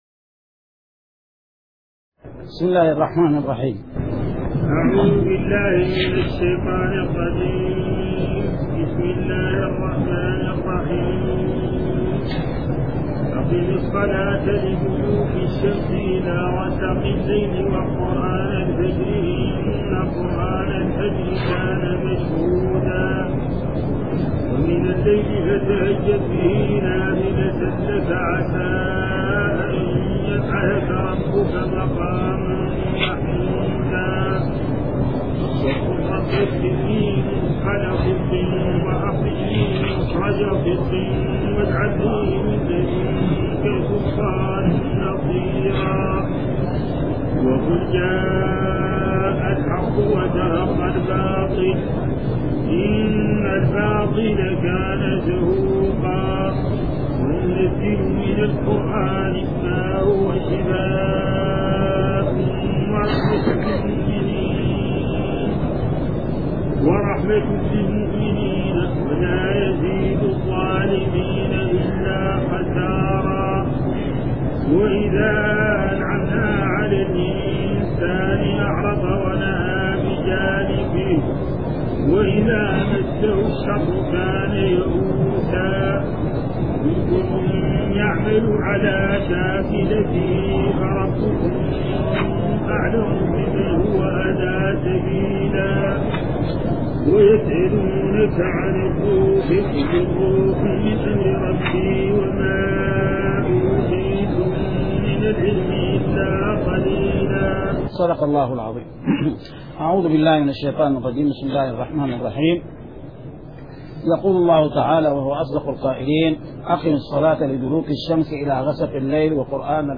من دروس الحرم المدنى الشريف